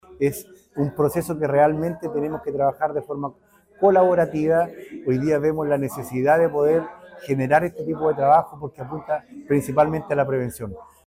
El alcalde Jorge Lozano Zapata destacó los alcances de esta medida.